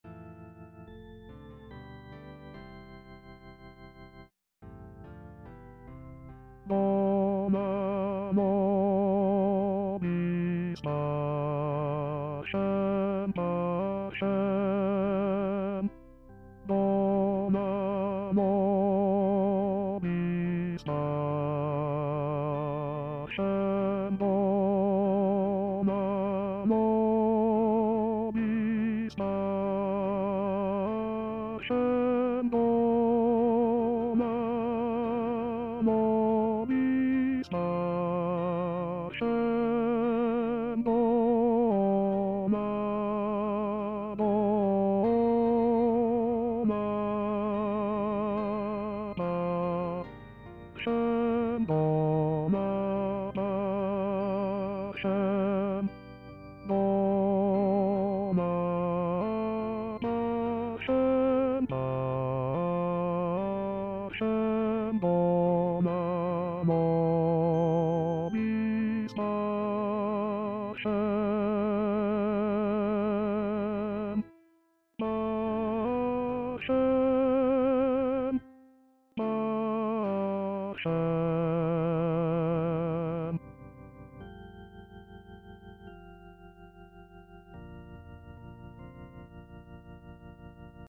Chanté:     S   A